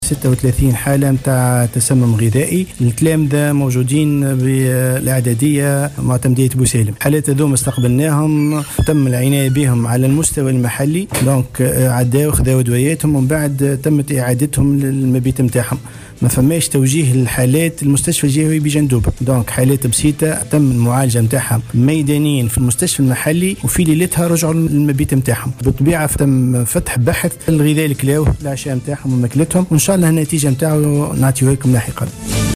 أكد المدير الجهوي للصحة بجندوبة، منجي السلطاني في تصريح لمراسل "الجوهرة أف أم" أنه تم فتح بحث إثر حادثة تسمّم 36 تلميذا في المدرسة الإعدادية طريق تونس ببوسالم.